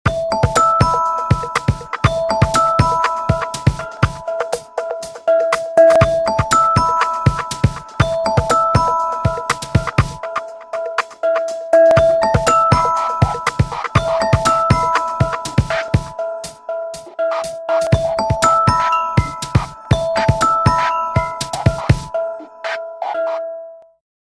nokia-lumia-ringabout_24556.mp3